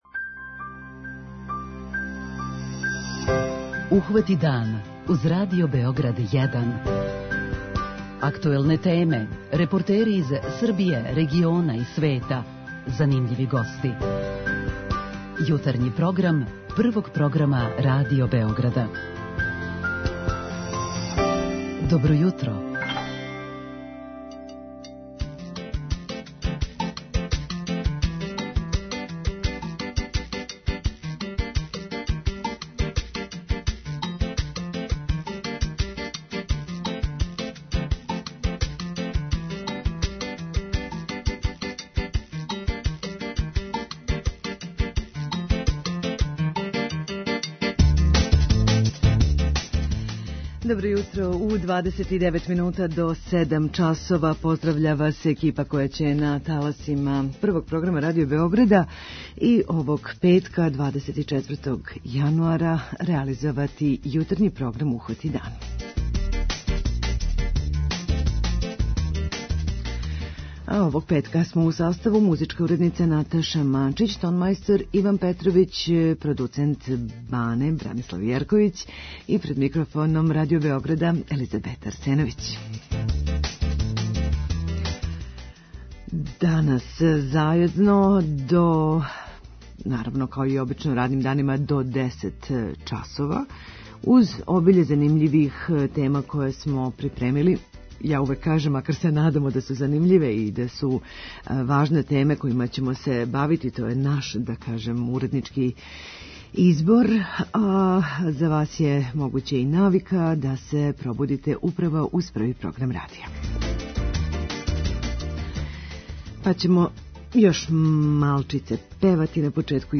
Бићете и у прилици да чујете разговор